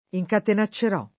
vai all'elenco alfabetico delle voci ingrandisci il carattere 100% rimpicciolisci il carattere stampa invia tramite posta elettronica codividi su Facebook incatenacciare v.; incatenaccio [ i j katen #©© o ], ‑ci — fut. incatenaccerò [ i j katena ©© er 0+ ]